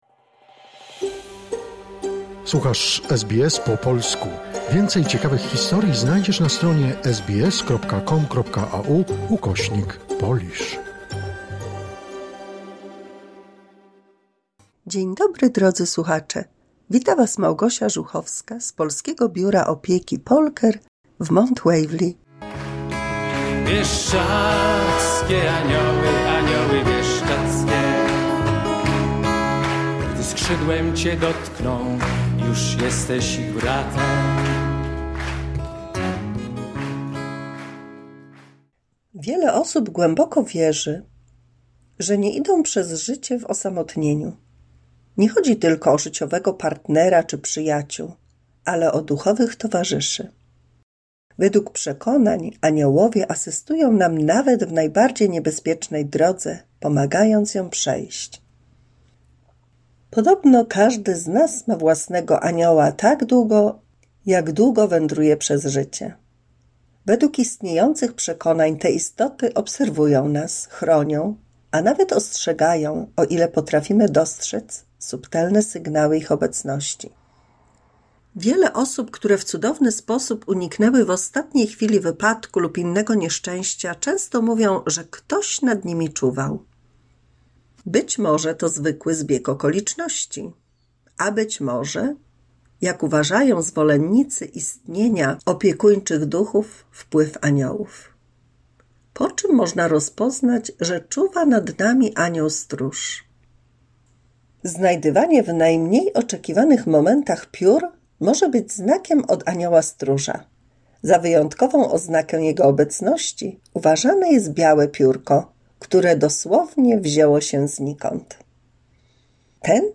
178 mini słuchowisko dla polskich seniorów